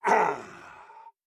tt_s_ara_cmg_bossCogAngry.ogg